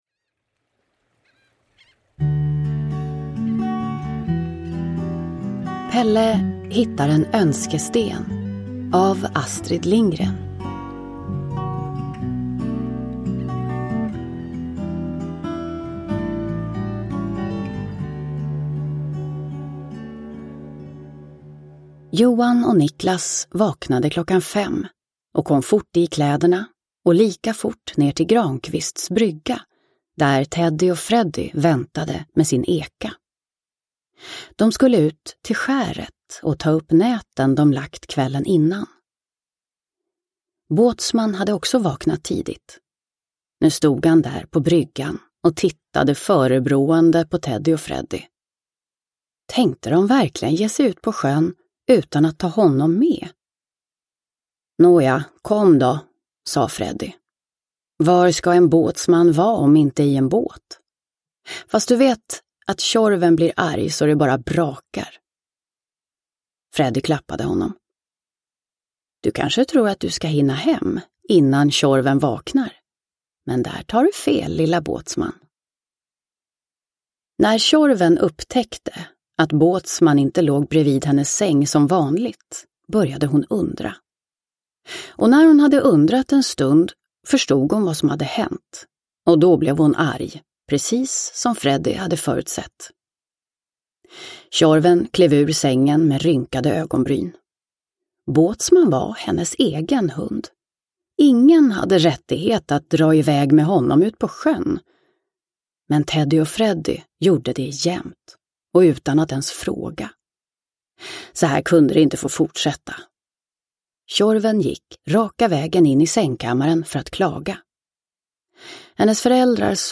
Pelle hittar en önskesten – Ljudbok – Laddas ner